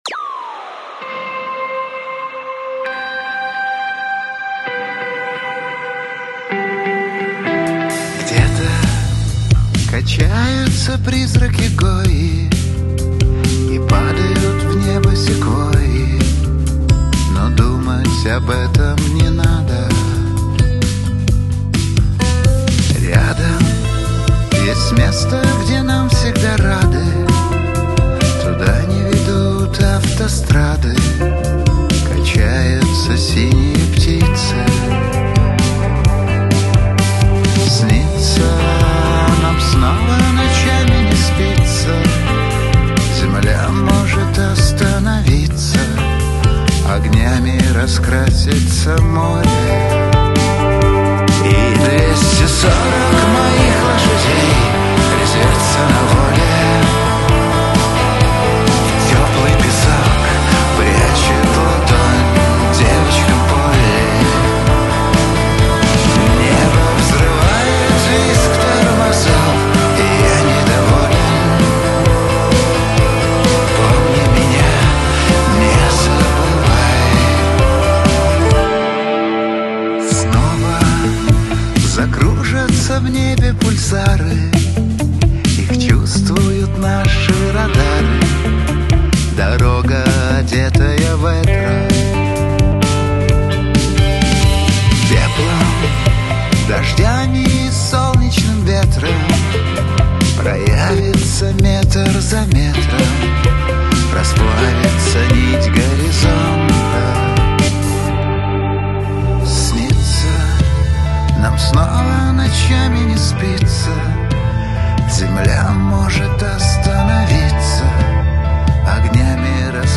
• Жанр: Поп, Русская музыка, Русский поп